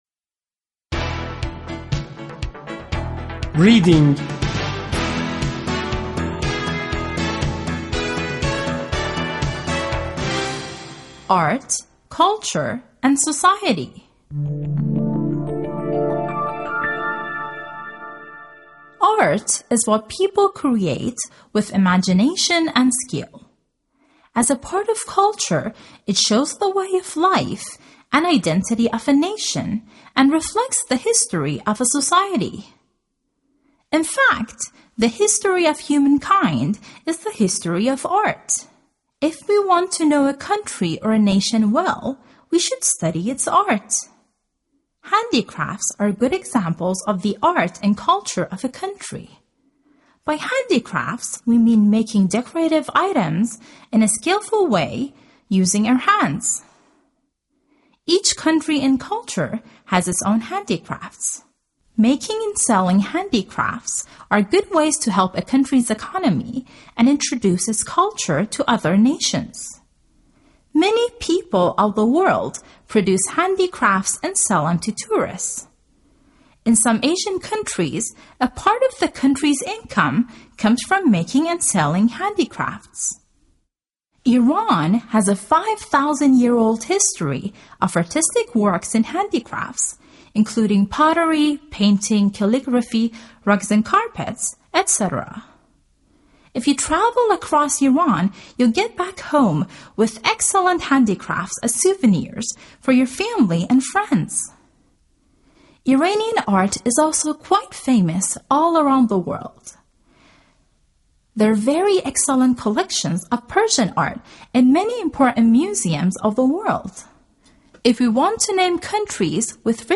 Reading
V.2-L.3-reading.mp3